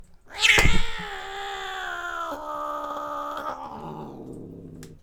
sound_library / animals / cats